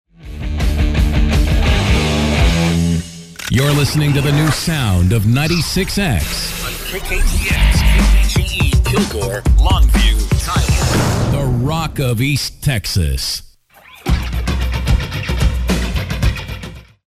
KKTX-FM Top of the Hour Audio: